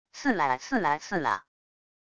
刺啦刺啦刺啦……wav音频